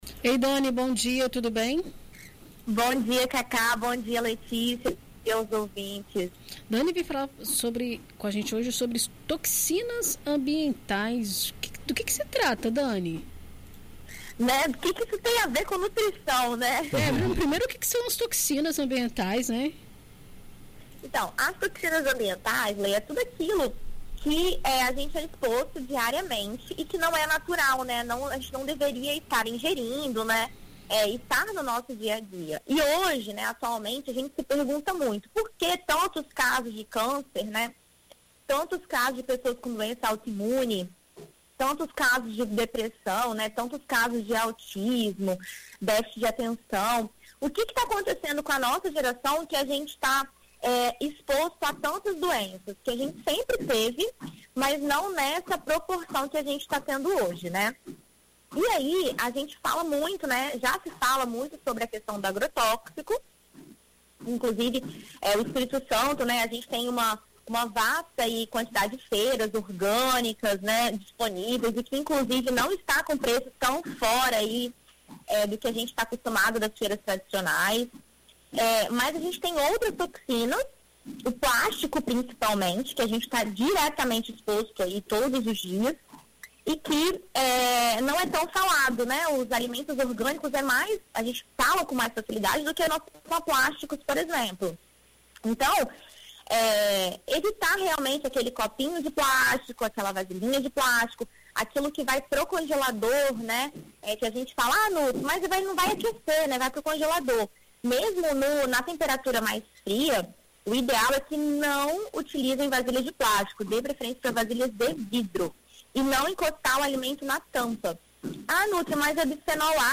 Na coluna desta semana na BandNews FM Espírito Santo